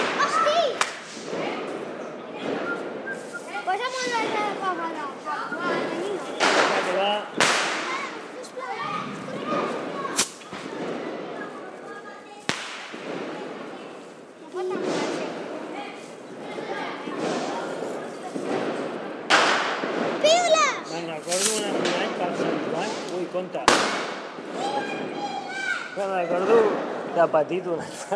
Cohete con petardeo intenso de fondo